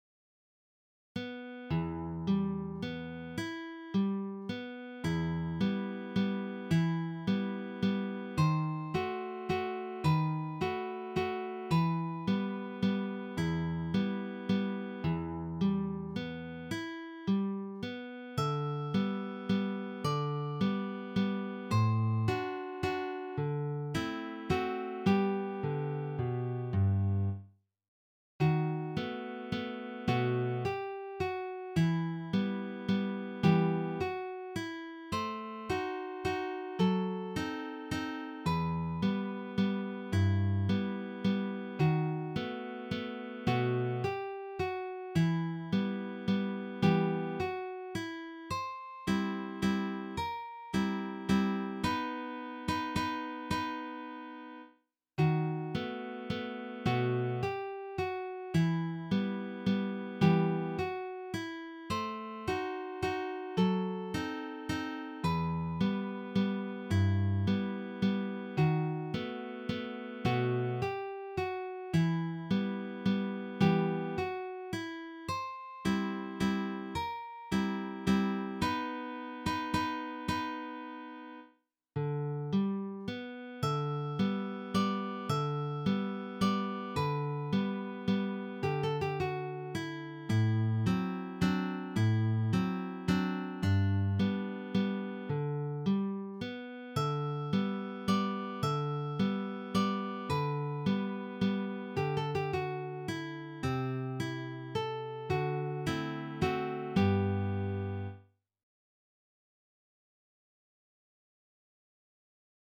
Vals en Mi for solo guitar by Daniel Fortea.